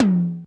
DMX TOM 4.wav